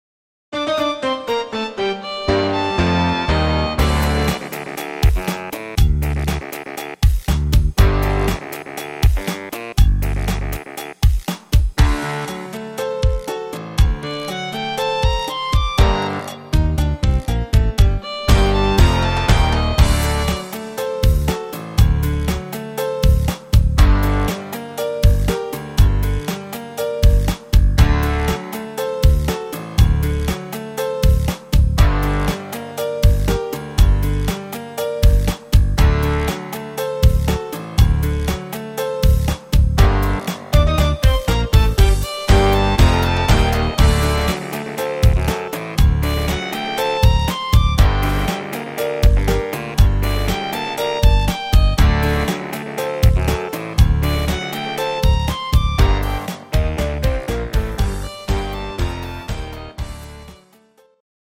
instr.Trompete